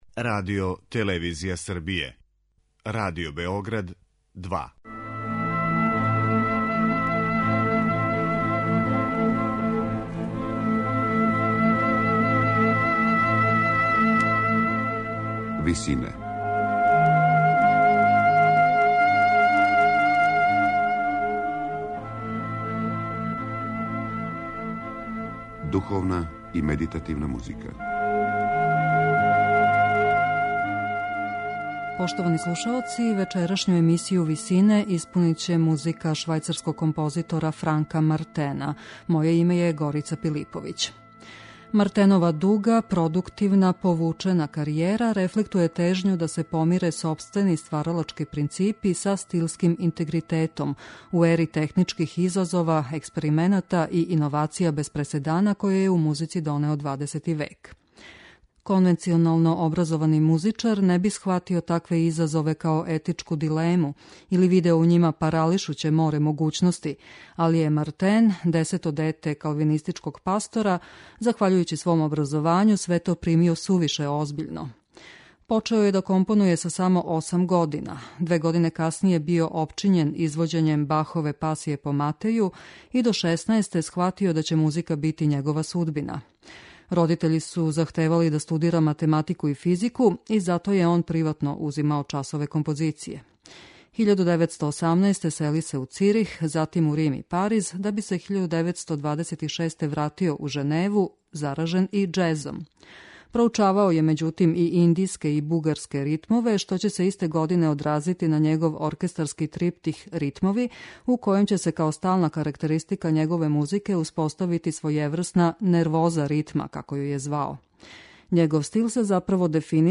ораторијум